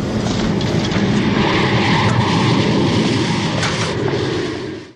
• AIRPLANE ENGINE ROARING.wav
AIRPLANE_ENGINE_ROARING_VLX.wav